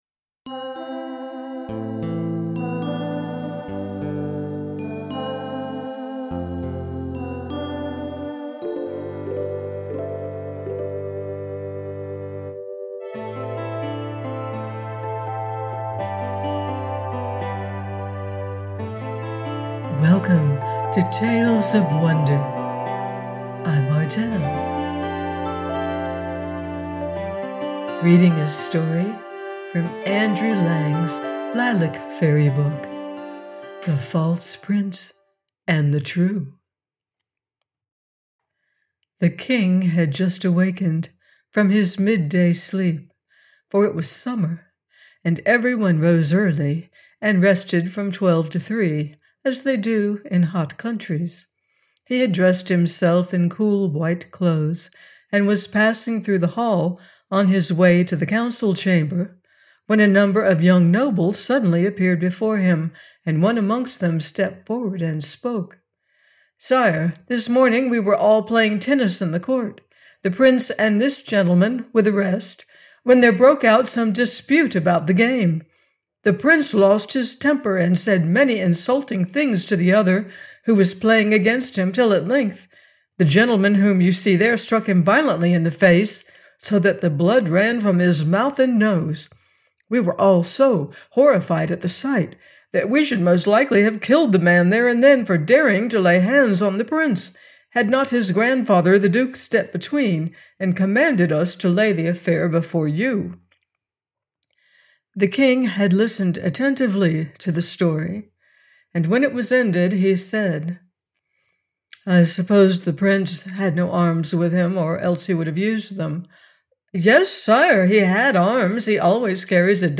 THE FALSE PRINCE AND THE TRUE – Lilac Fairy Book - audiobook